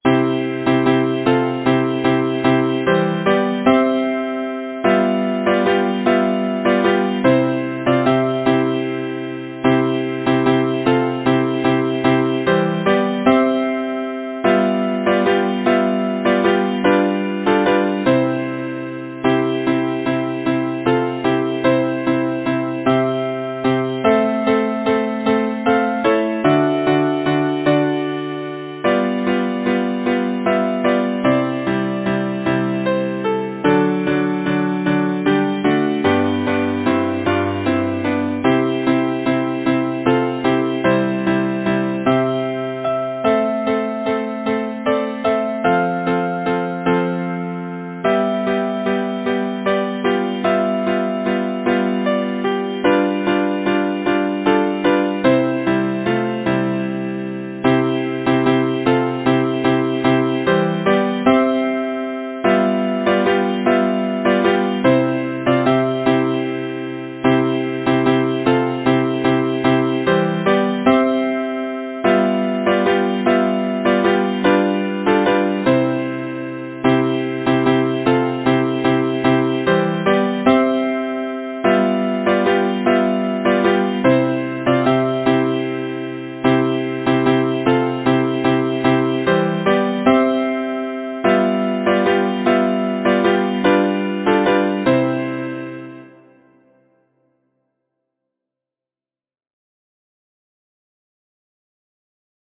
Title: Row the boat lightly Composer: Alexander B. Morton Lyricist: Number of voices: 4vv Voicing: SATB Genre: Secular, Partsong
Language: English Instruments: A cappella